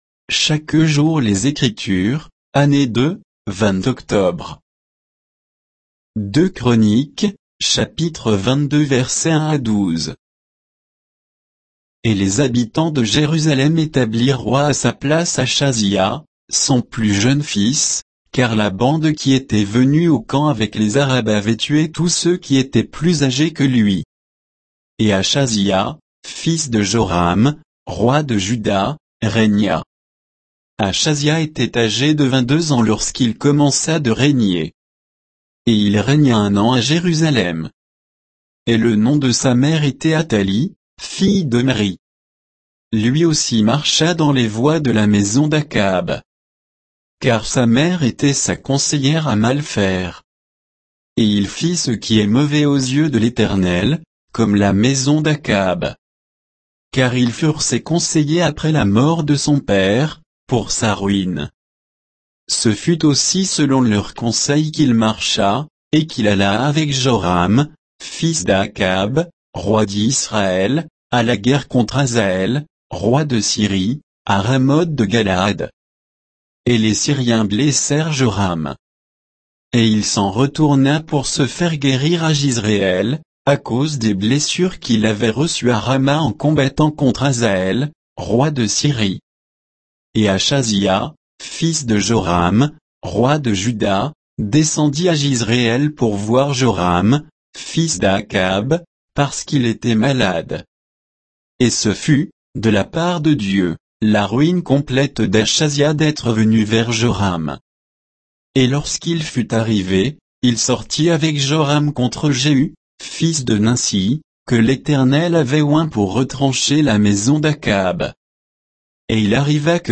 Méditation quoditienne de Chaque jour les Écritures sur 2 Chroniques 22, 1 à 12